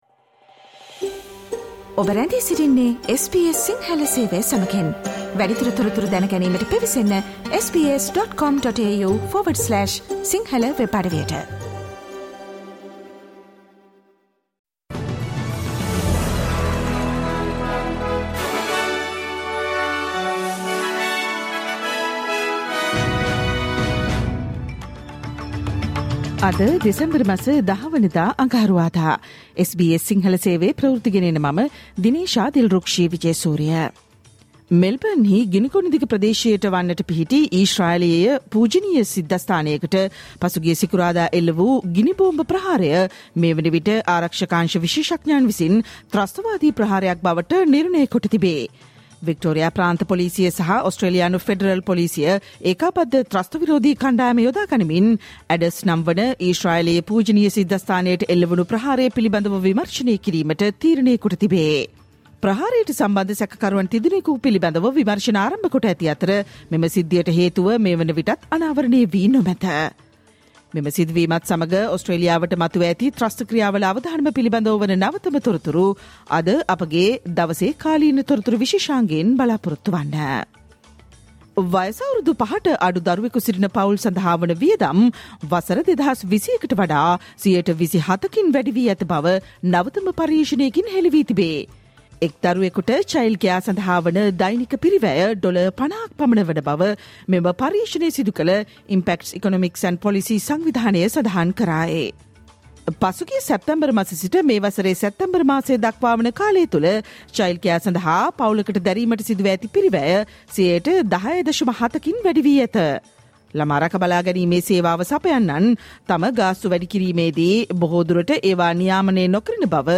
Australian news in Sinhala, foreign and sports news in brief.